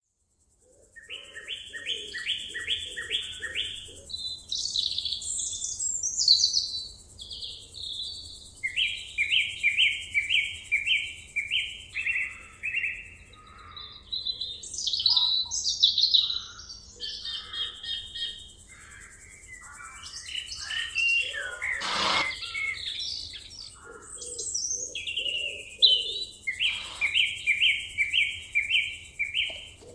Country stables
Relax to the sound of a country stable with horses galloping, birds singing & a general rural ambience
Countrystables1.mp3